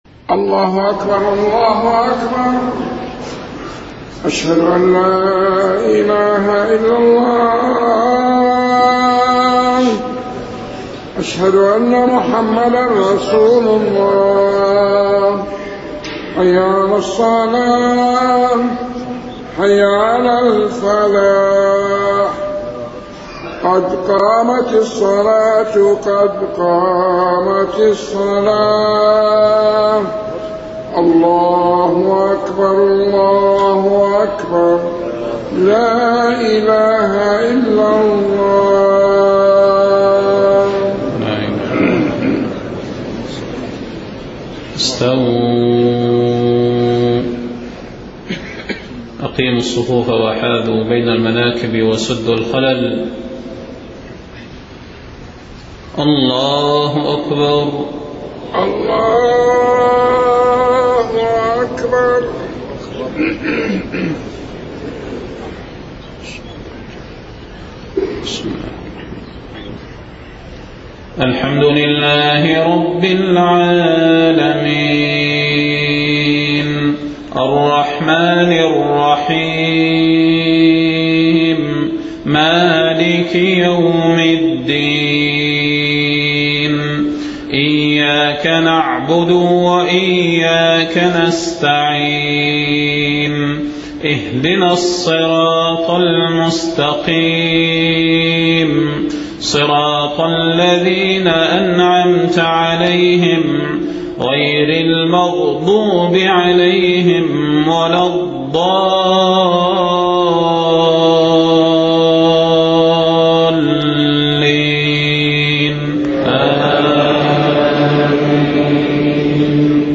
صلاة المغرب 1 صفر 1430هـ سورتي القارعة و التكاثر > 1430 🕌 > الفروض - تلاوات الحرمين